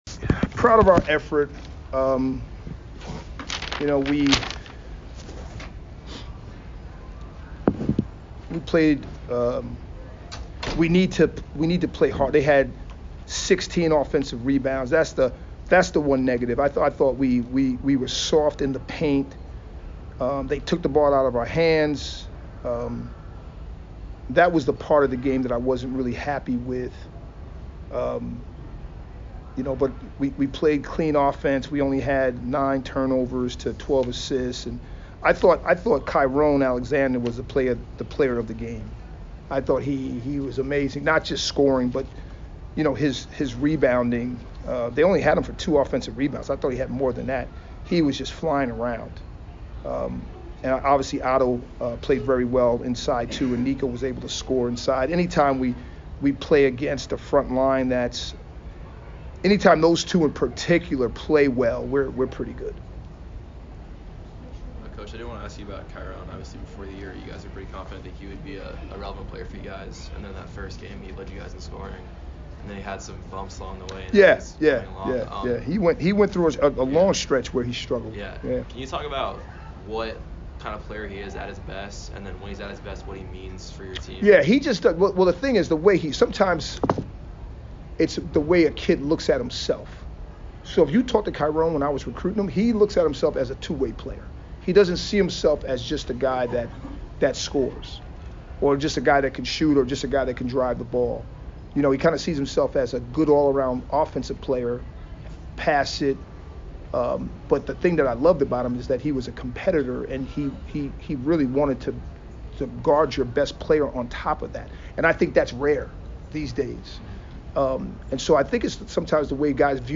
Navy Postgame Interview